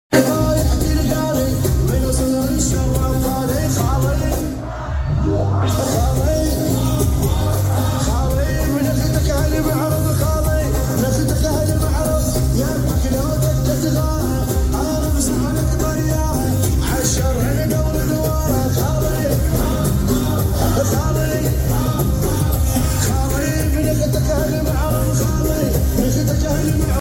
Non-stop energy and crazy vibes on the dance floor